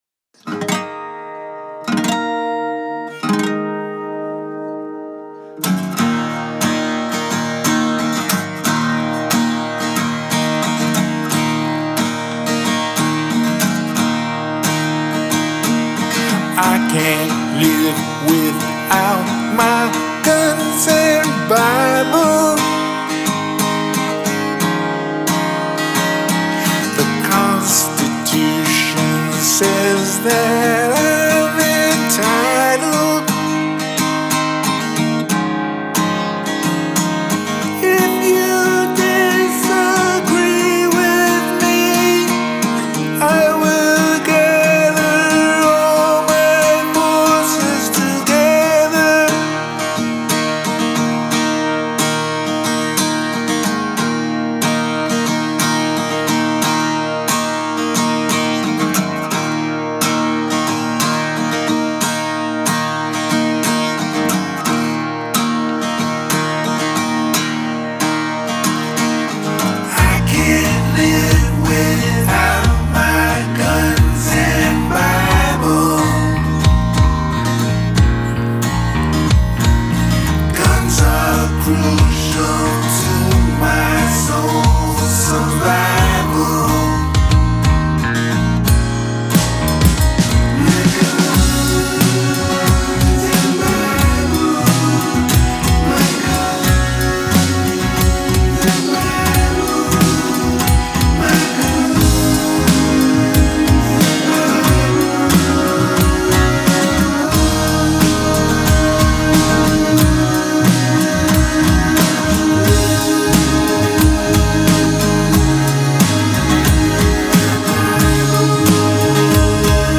Acid rock sort of ?